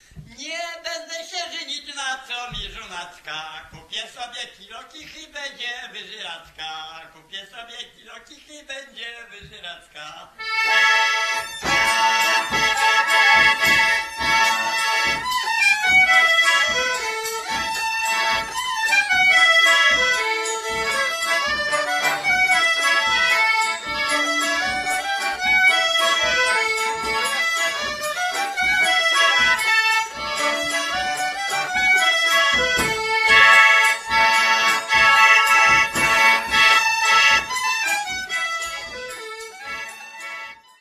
Oberek (Michowice, 1995)
harmonia 3-rzędowa, 24-basowa "Glanowski"
skrzypce
baraban ze stalką